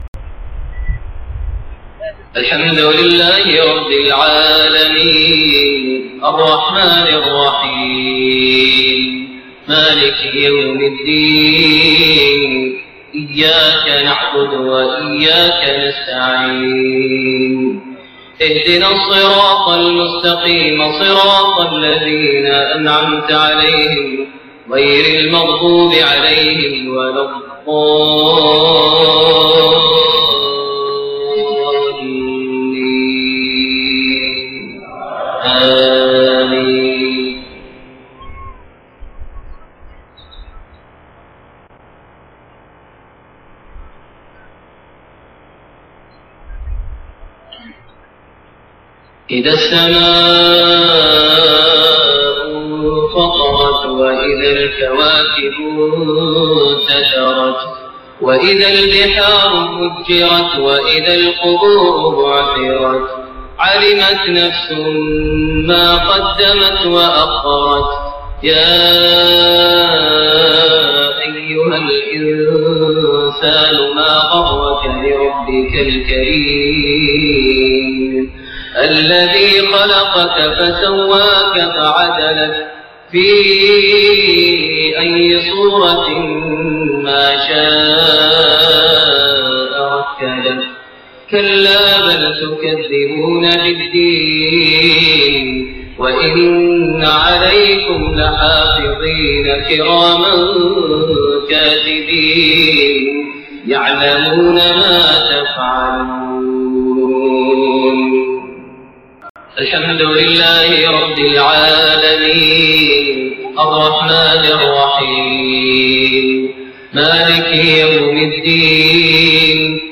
Maghrib prayer Surat Al-Infitaar > 1429 H > Prayers - Maher Almuaiqly Recitations